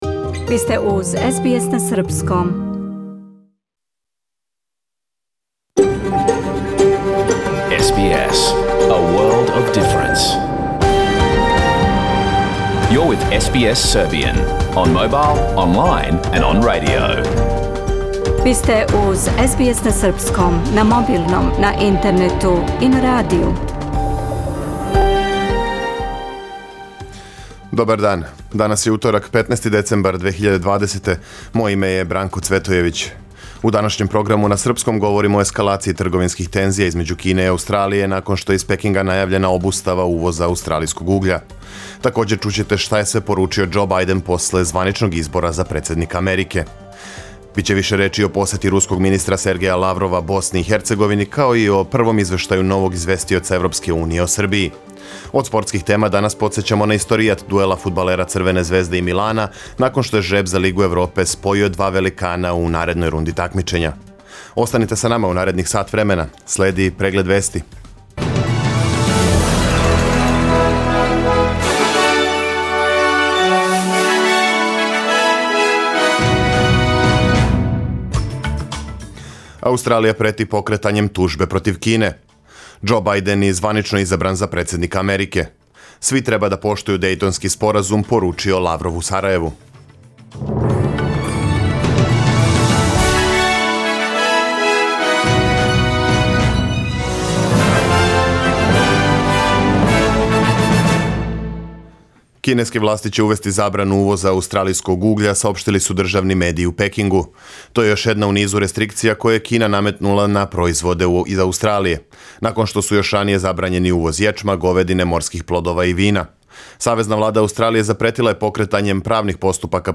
Serbian News Bulletin Source: SBS Serbian